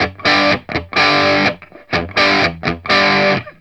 RIFF1-125G.A.wav